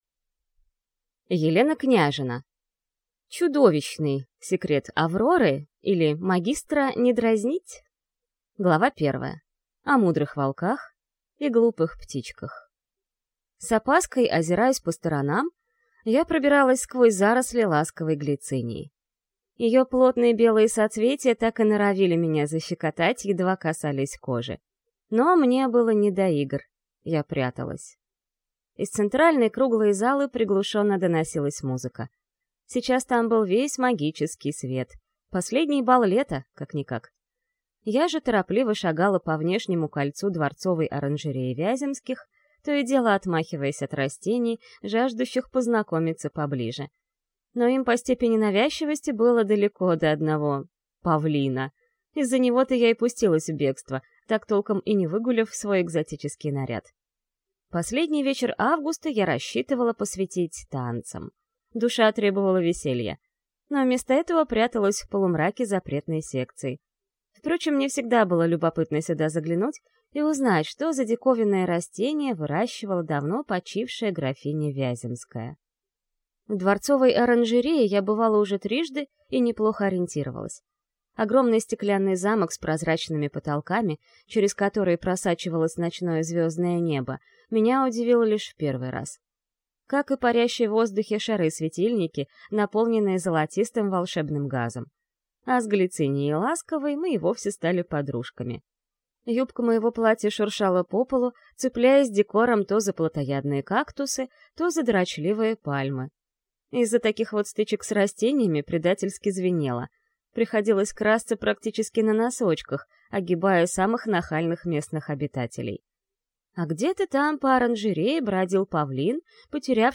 Аудиокнига «Чудовищный» секрет Авроры, или Магистра не дразнить!